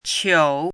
chinese-voice - 汉字语音库
qiu3.mp3